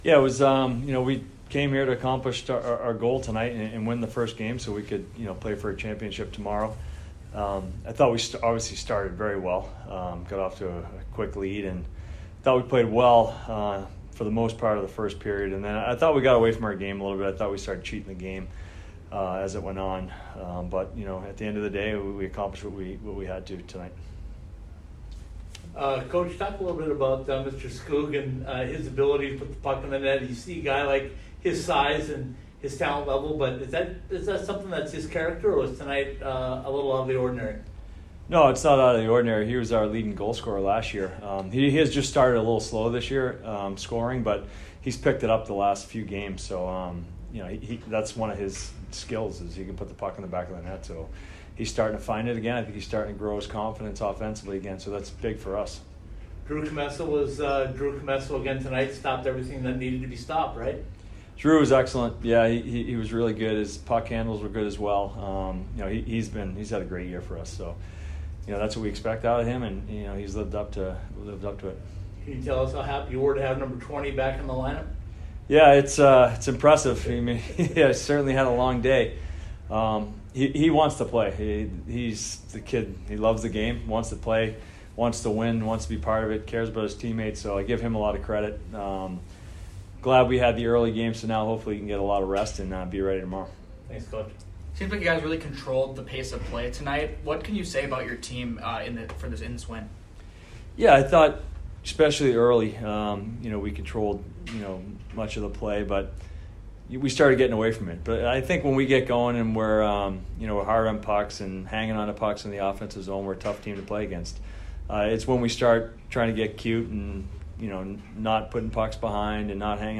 Air Force Postgame Interview